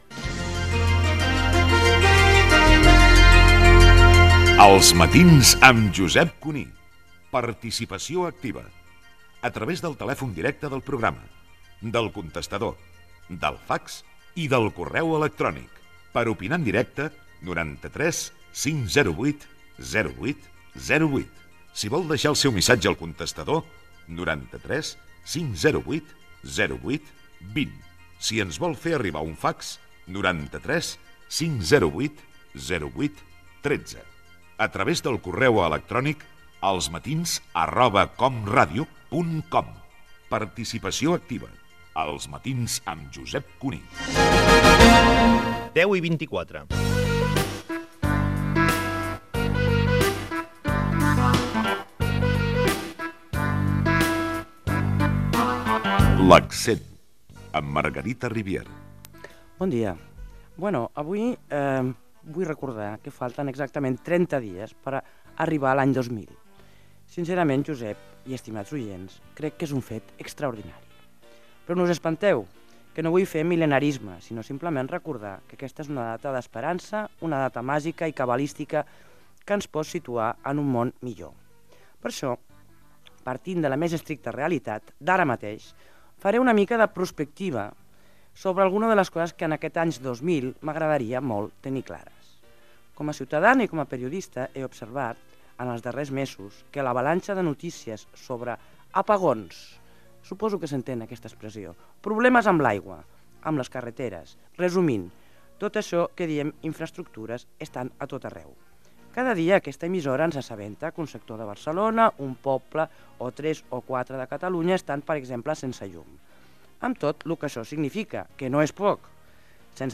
Info-entreteniment
Fragment extret de l'arxiu sonor de COM Ràdio.